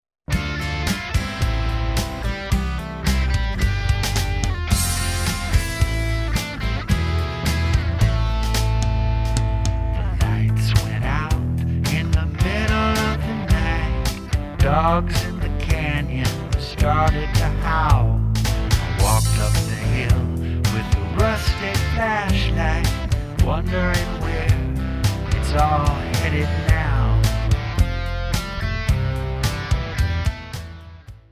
Written, performed, recorded and mixed